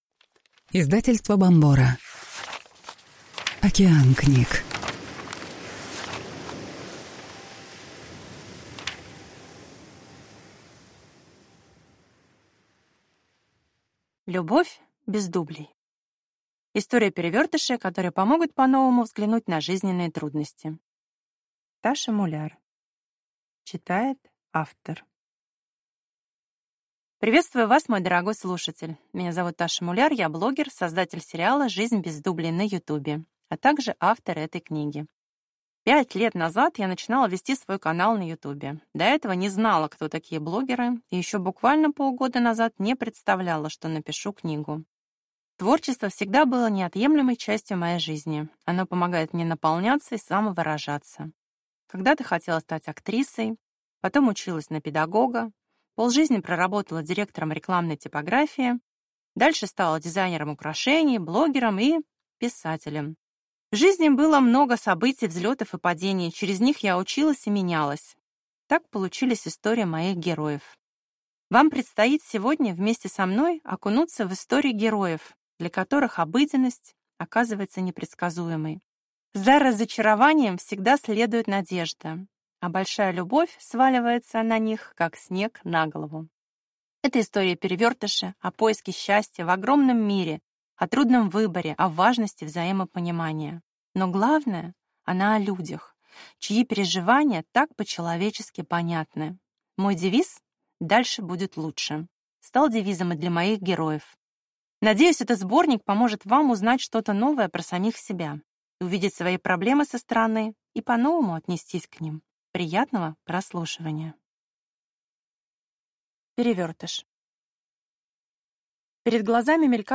Внимание! Содержит нецензурную брань.